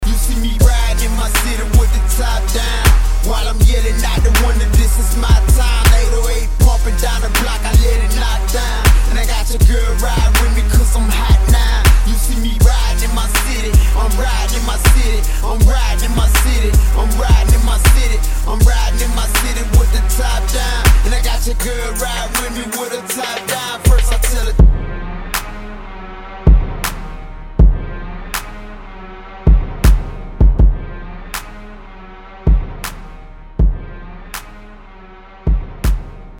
• Качество: 320, Stereo
Гангста Рэп